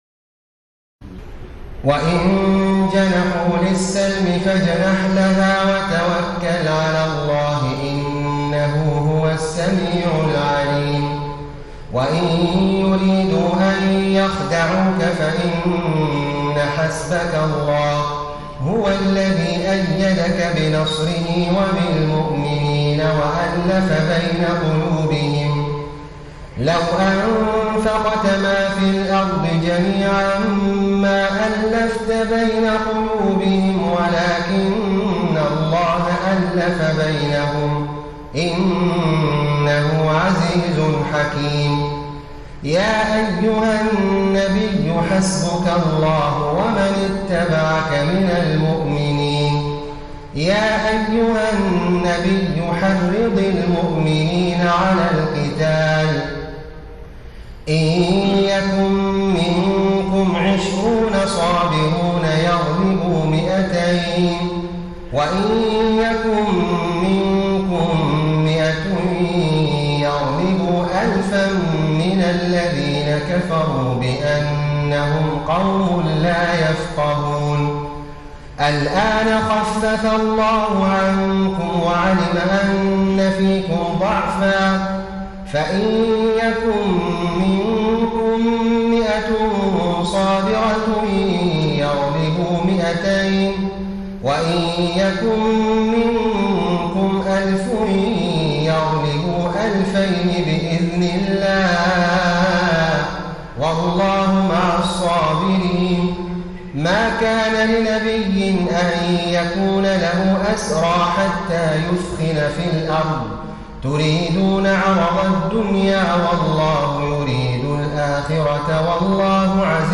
تراويح الليلة العاشرة رمضان 1433هـ من سورتي الأنفال (61-75) و التوبة (1-52) Taraweeh 10 st night Ramadan 1433H from Surah Al-Anfal and At-Tawba > تراويح الحرم النبوي عام 1433 🕌 > التراويح - تلاوات الحرمين